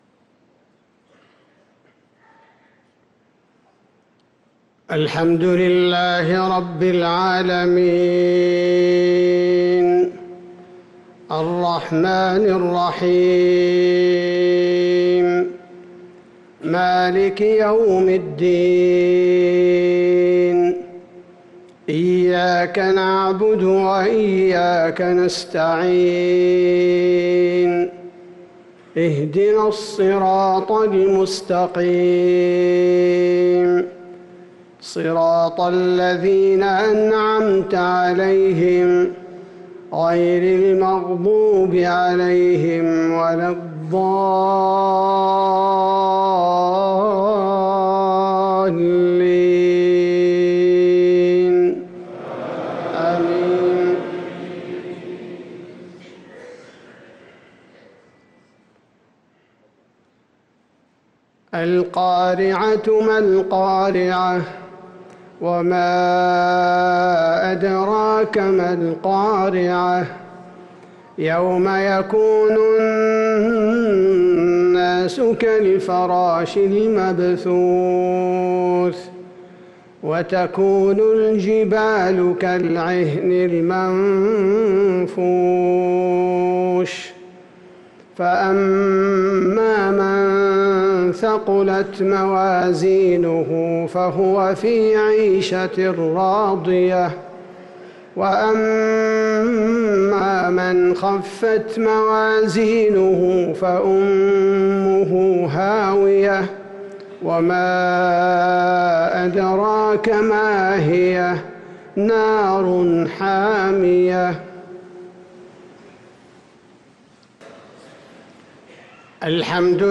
صلاة المغرب للقارئ عبدالباري الثبيتي 2 ربيع الأول 1445 هـ
تِلَاوَات الْحَرَمَيْن .